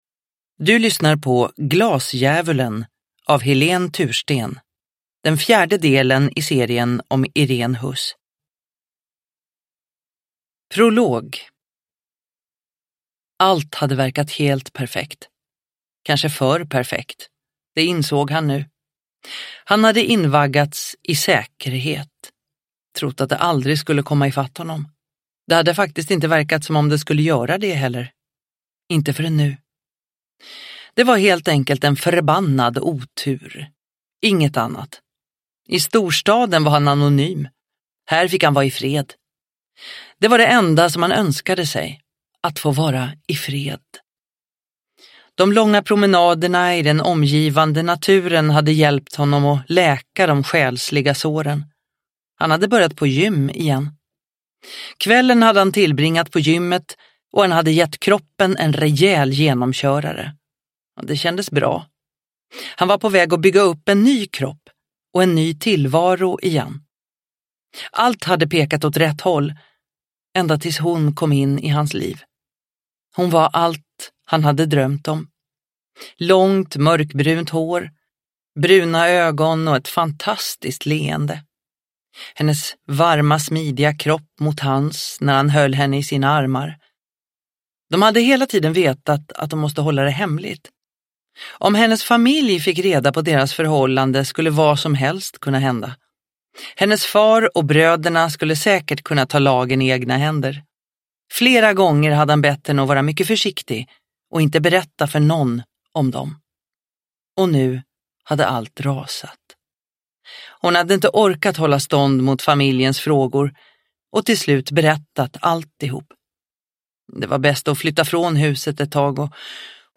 Glasdjävulen – Ljudbok – Laddas ner